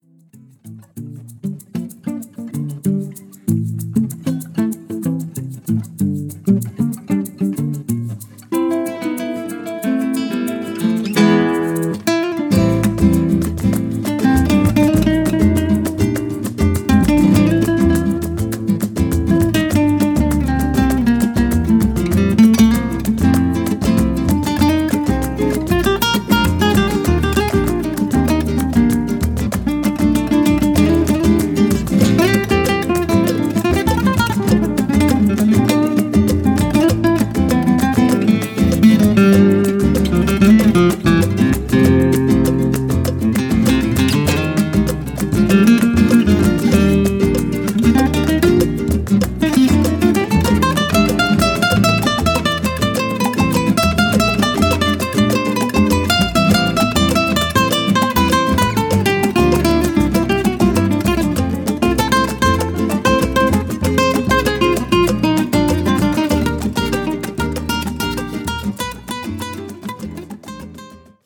gitarre, bass, percussion
flöte
klarinette
cello